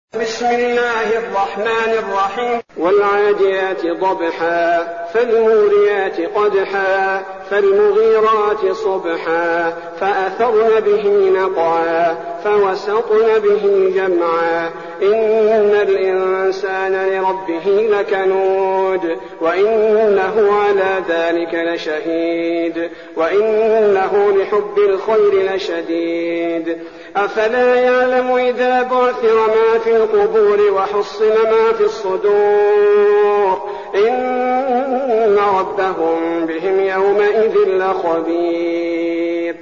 المكان: المسجد النبوي الشيخ: فضيلة الشيخ عبدالباري الثبيتي فضيلة الشيخ عبدالباري الثبيتي العاديات